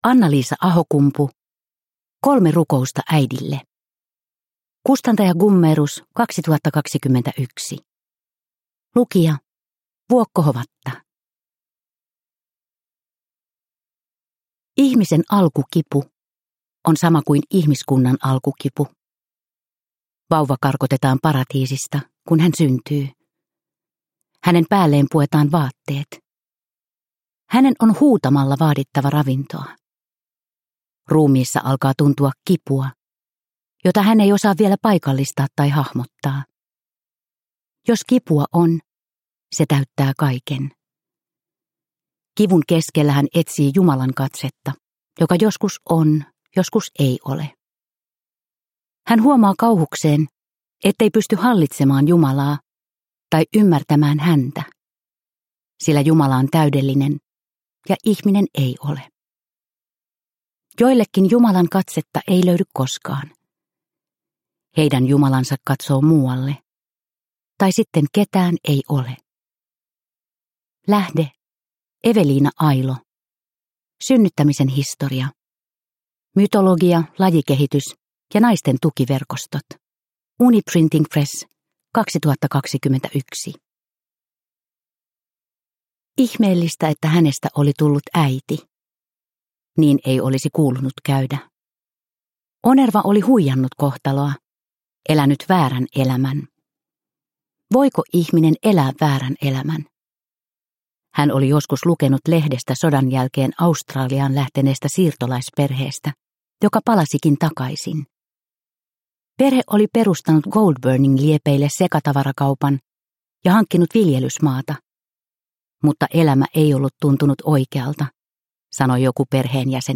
Kolme rukousta äidille – Ljudbok – Laddas ner